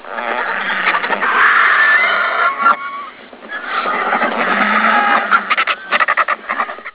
vultures_k.wav